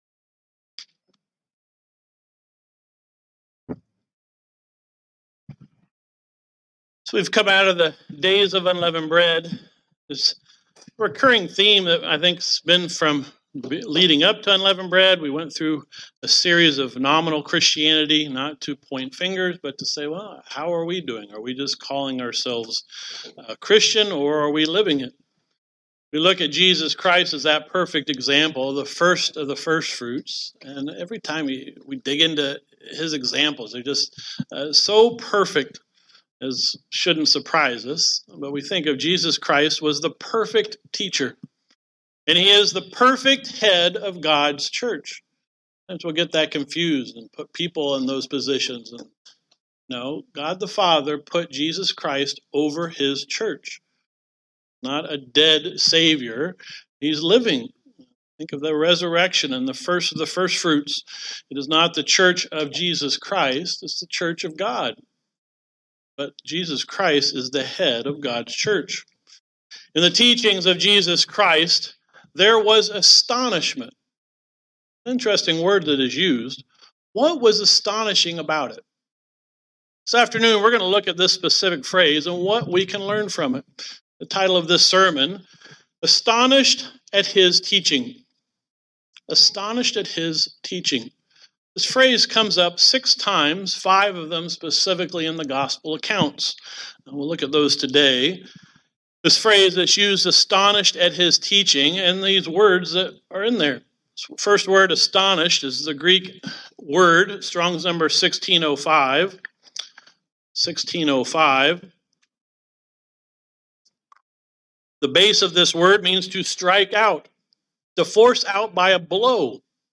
What was astonishing about the teaching of Jesus Christ? This sermon looks at this phrase in the Bible and what we can learn from it.